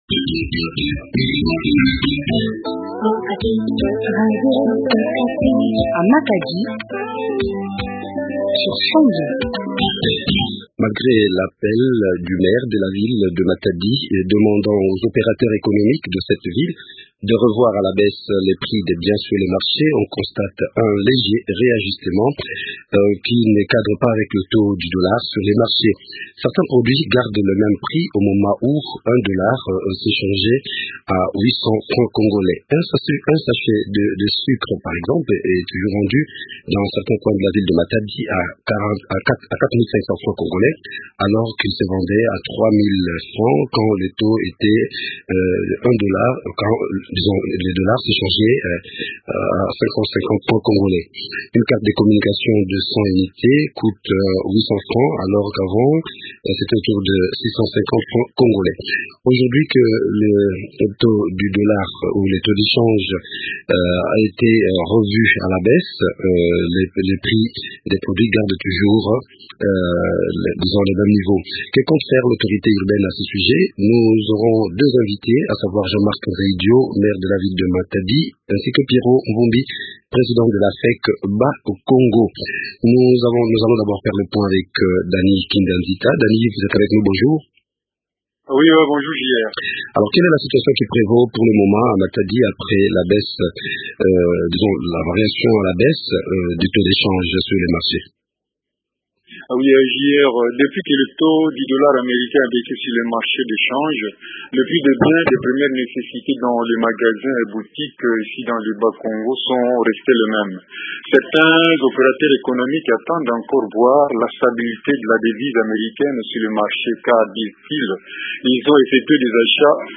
font le point avec Jean Marc Nzeyidio, Maire de la ville de Matadi